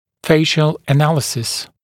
[‘feɪʃl ə’næləsɪs][‘фэйш(л э’нэлэсис]анализ лица